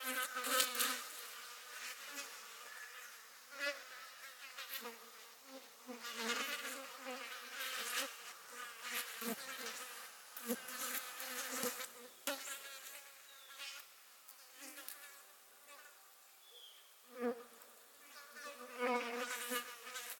insectday_10.ogg